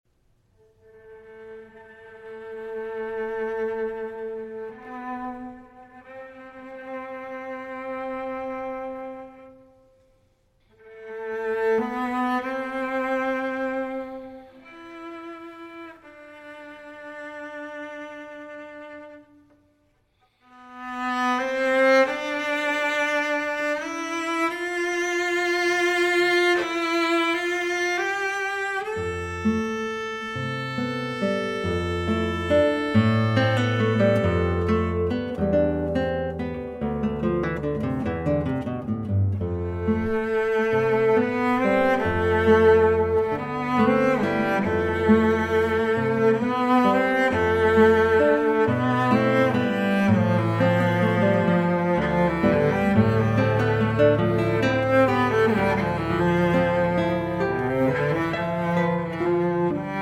for Cello and Guitar